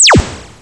se_cancel00.wav